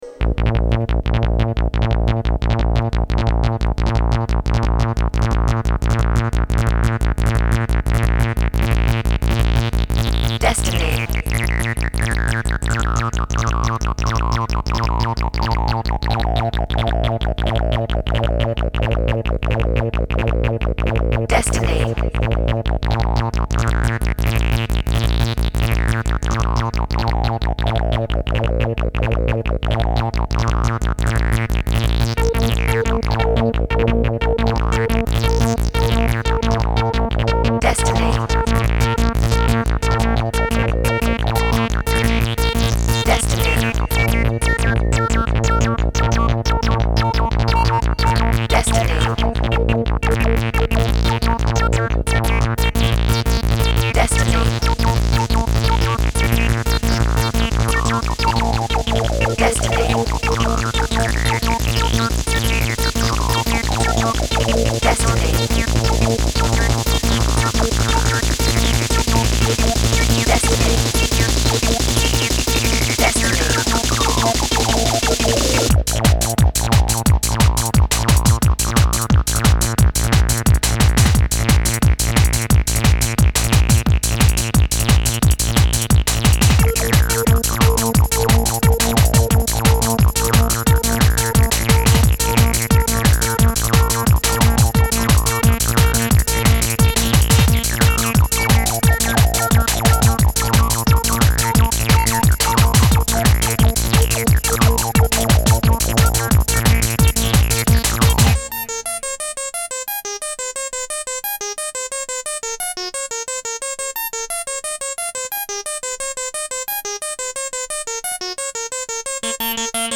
Genre: Hard Trance, Techno, Rave, Hardcore, Dance.